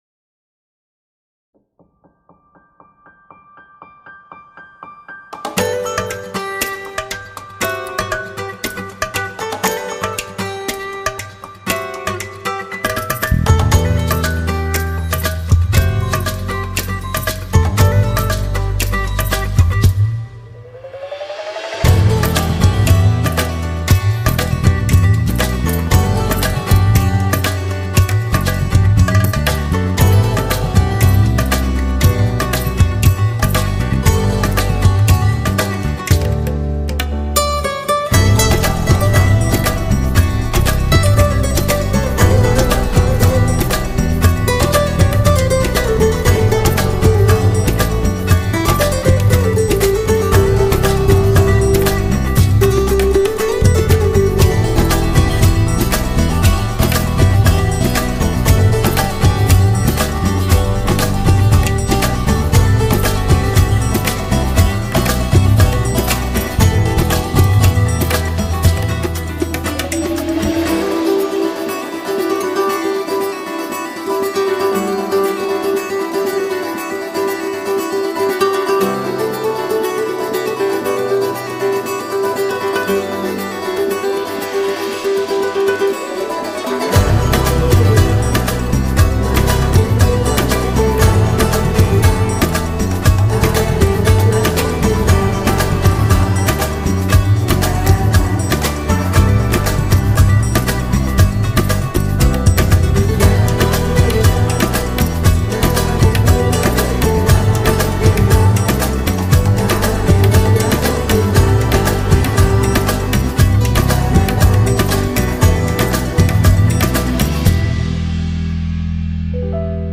tema dizi müziği, mutlu huzurlu rahatlatıcı fon müziği.